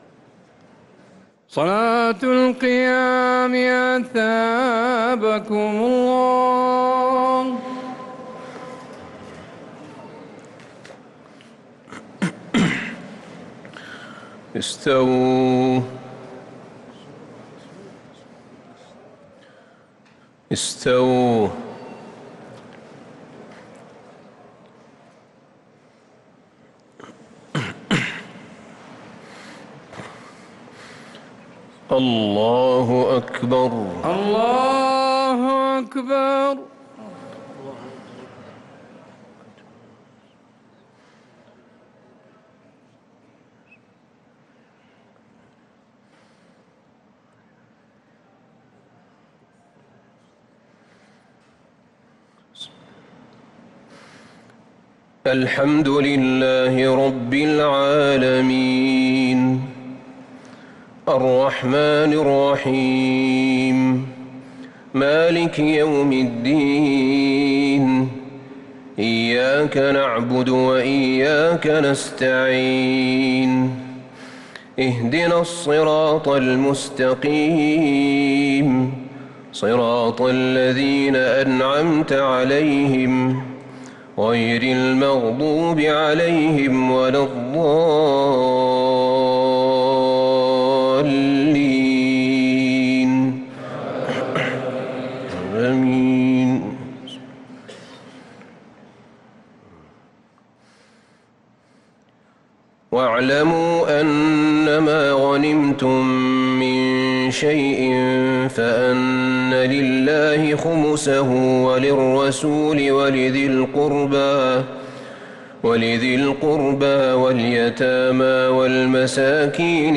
صلاة التراويح ليلة 13 رمضان 1443 للقارئ أحمد بن طالب حميد - الثلاث التسليمات الأولى صلاة التراويح | تراويح رمضان | تِلَاوَات الْحَرَمَيْن
صلاة التراويح ليلة 13 رمضان 1443 للقارئ أحمد بن طالب حميد - الثلاث التسليمات الأولى صلاة التراويح